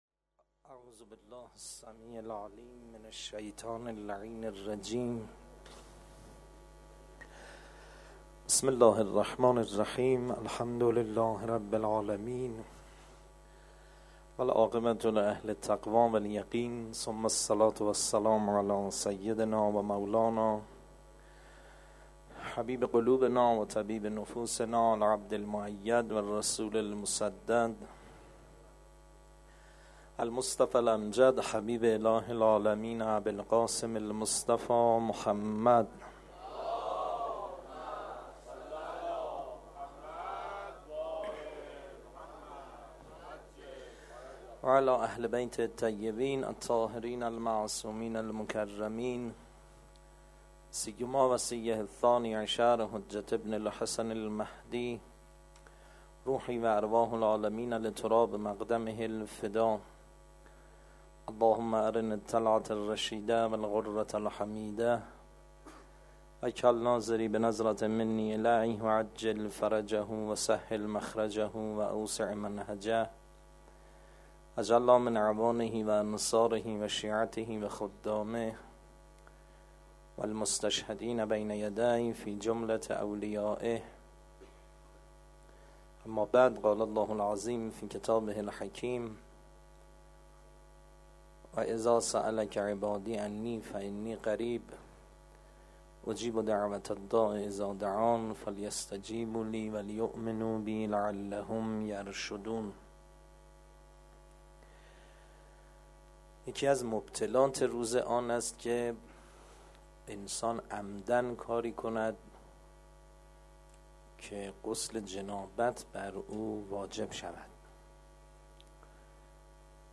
چندرسانه ای Layer ۱ ریشه > ماه رمضان > مناجات > 1391 > مراسم شب بیستم رمضان > سخنرانی > 1- سخنرانی بخش اول